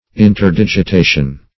Search Result for " interdigitation" : The Collaborative International Dictionary of English v.0.48: Interdigitation \In`ter*dig`i*ta"tion\, n. (Anat.)